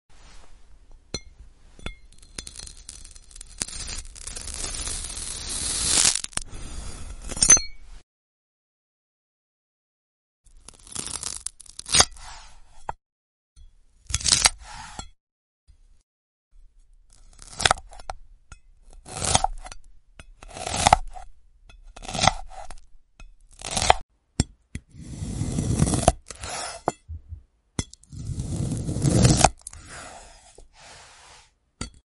ASMR glass cutting 🔪✨ Glass sound effects free download
Experience the oddly satisfying sounds of glass cutting followed by the soft, juicy ASMR of strawberry slicing. Perfect for relaxation, tingles, and calming vibes.